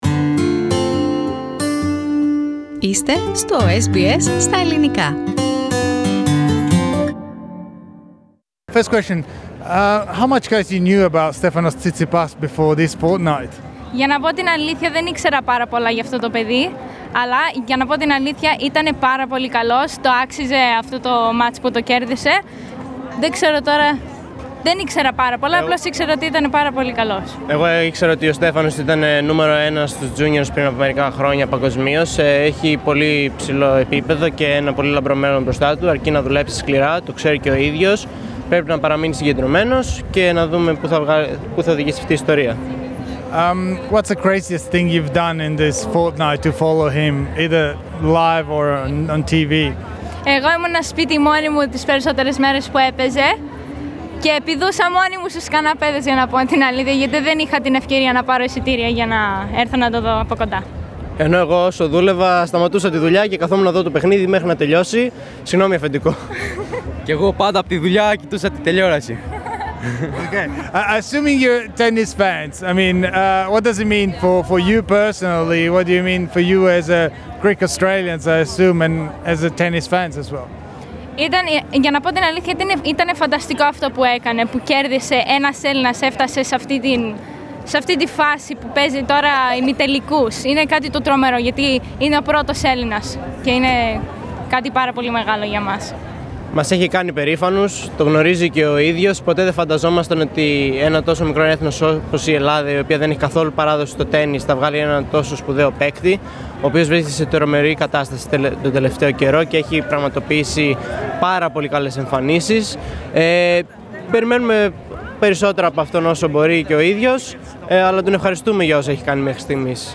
Greek Australian fans talk about Tsitsipas winning run at the Australian Open